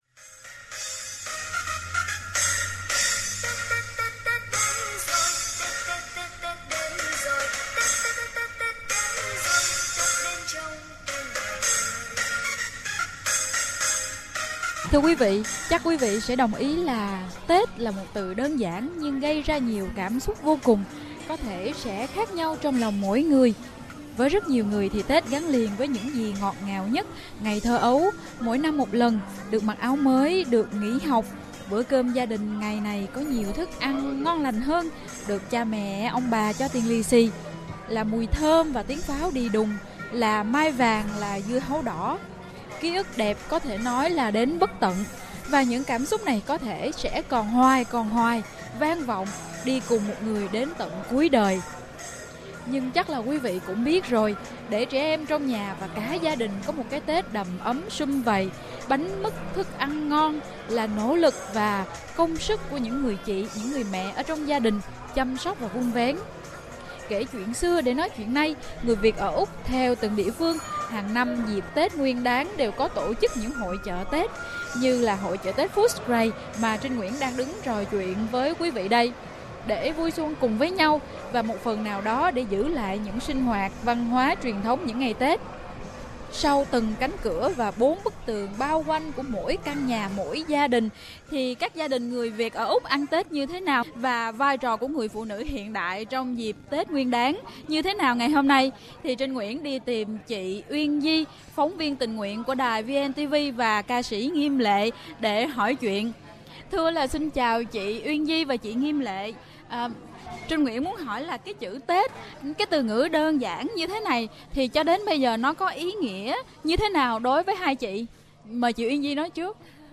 trò chuyện